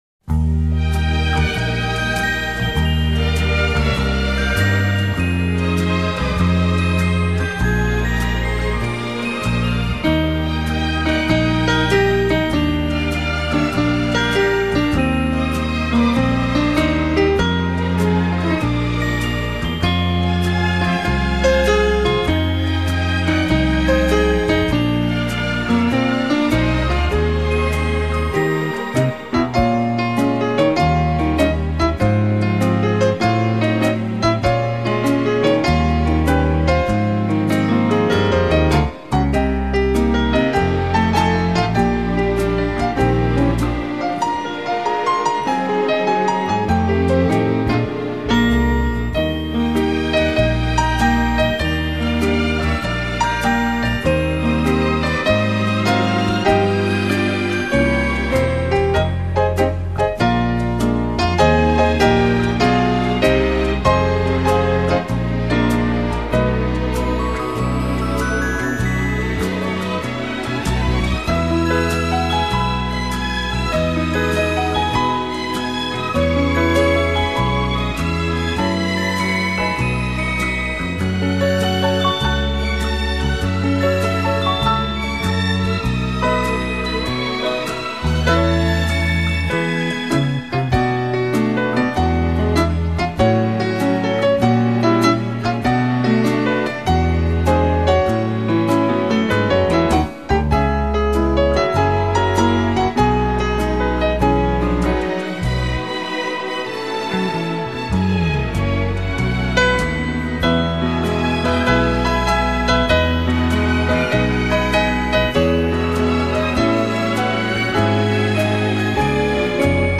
改编法国当今流行香颂曲弦乐 长笛萨克斯风排笛吉他演出